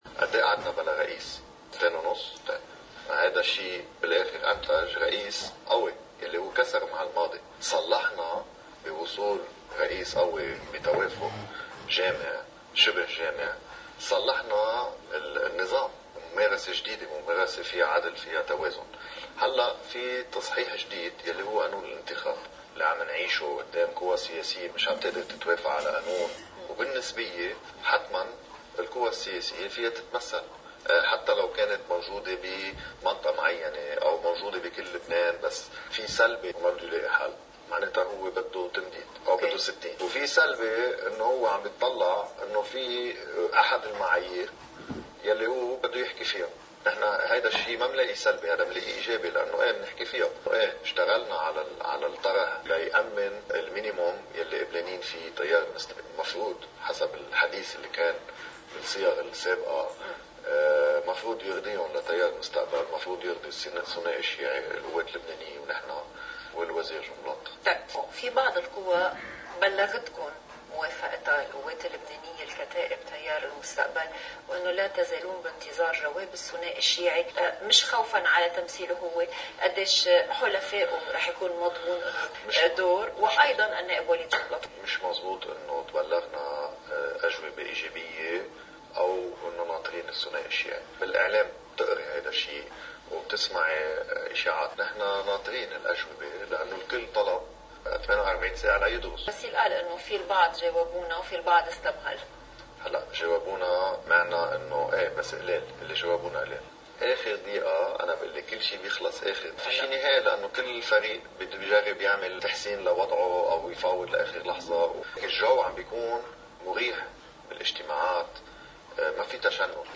مقتطف من حديث الوزير السابق “نقولا صحناوي” ضمن برنامج “نهاركم سعيد” على قناة الـ”LBC”: